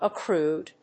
/ʌˈkrud(米国英語), ʌˈkru:d(英国英語)/
フリガナアクルード